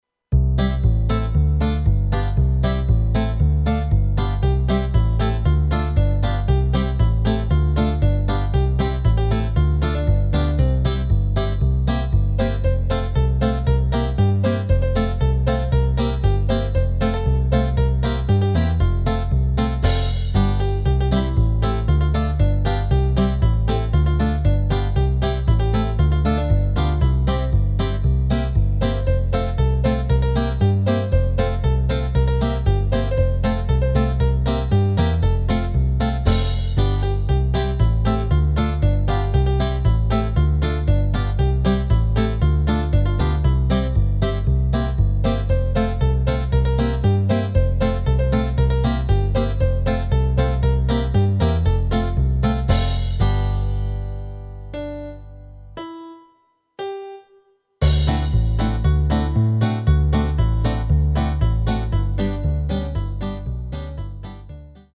Rubrika: Folk, Country
Karaoke